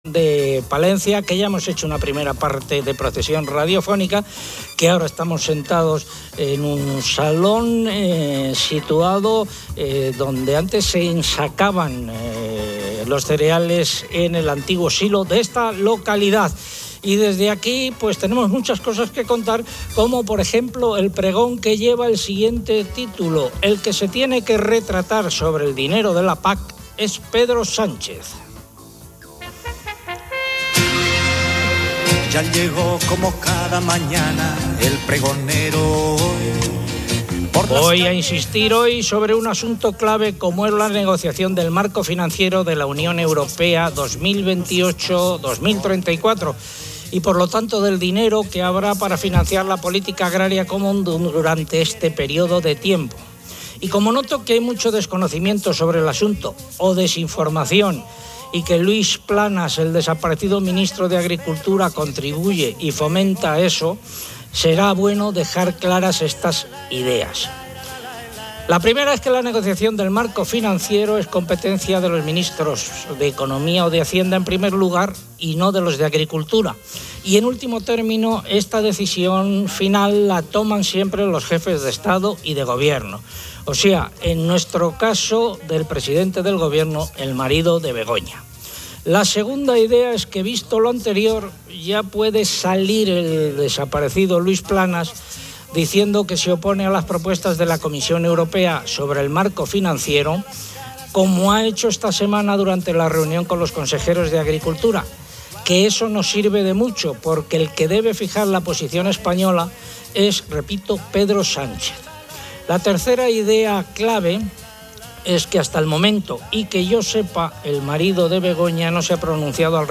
El programa comienza desde Torquemada, Palencia, donde se debate sobre la política agraria común (PAC).